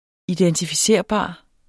Udtale [ idεntifiˈseɐ̯ˀˌbɑˀ ]